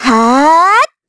voices / heroes / kr
Xerah-Vox_Casting3_kr.wav